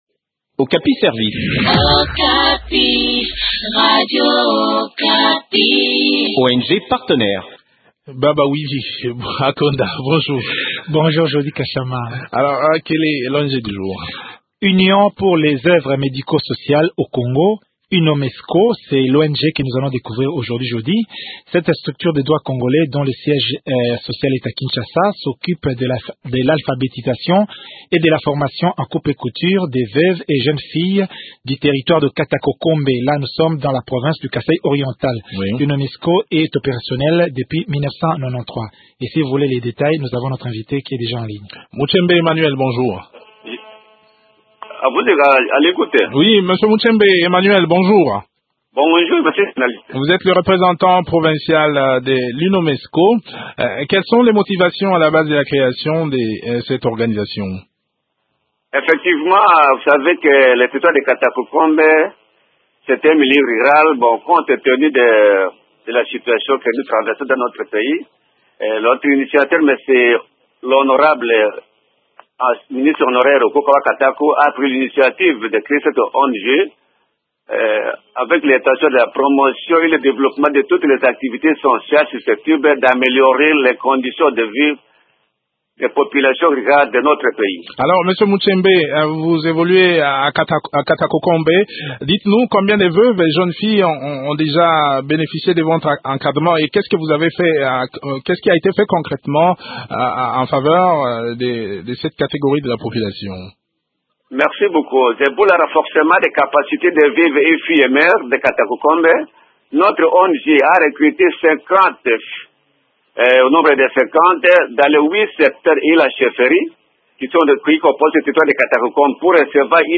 fait le point de leurs activités au micro